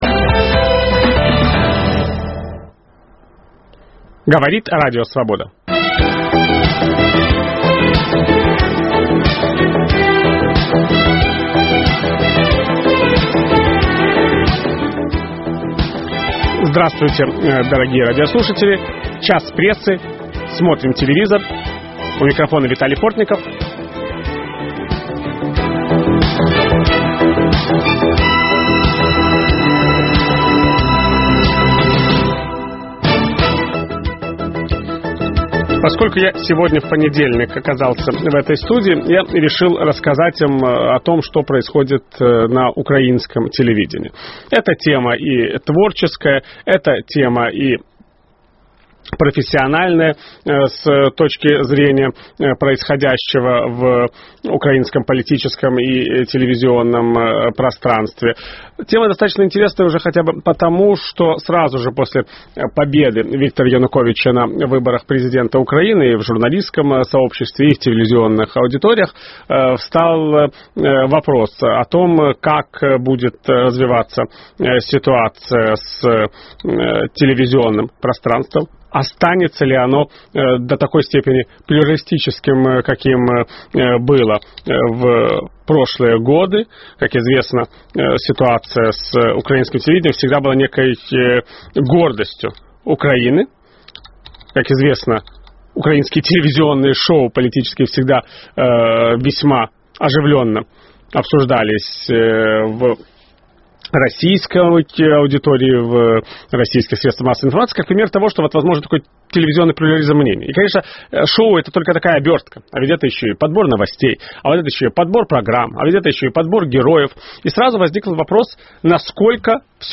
Почему Служба безопасности Украины вмешивается в дела украинского телевидения? В программе дискутируют: